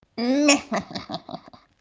Play, download and share meheheh original sound button!!!!
mehehehe.mp3